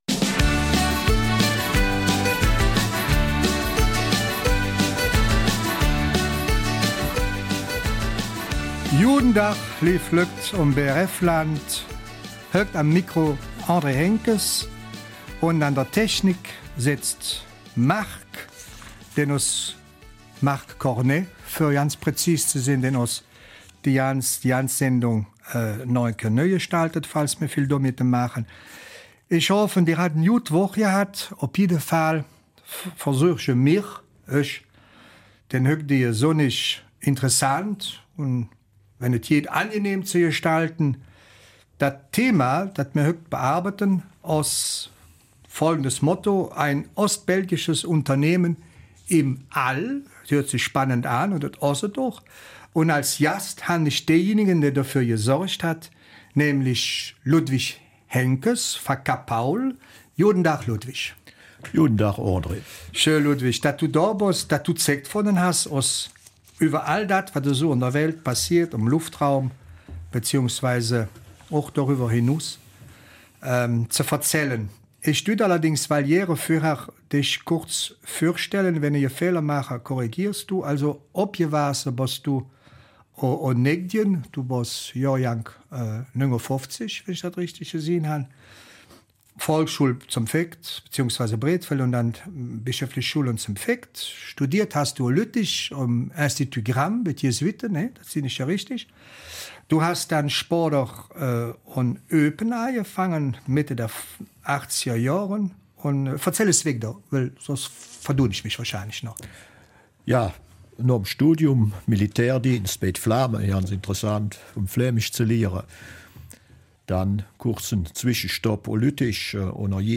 Eifeler Mundart